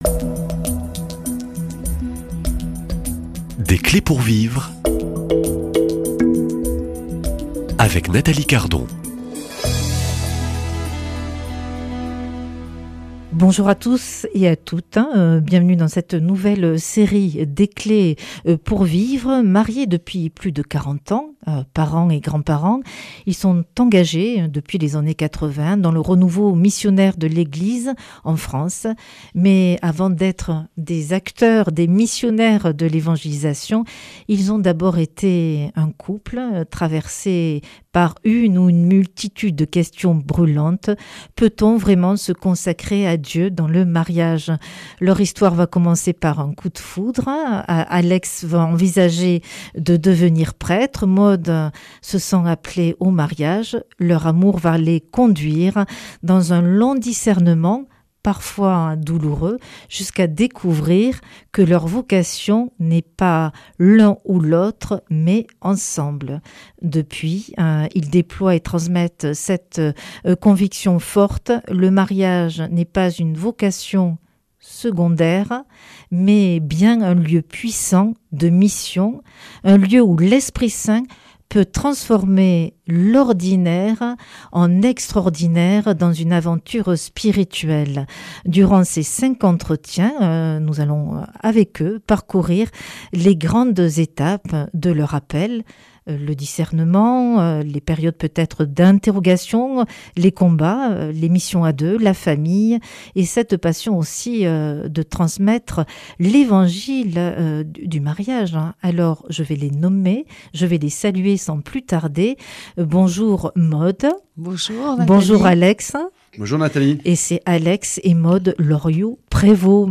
Témoignages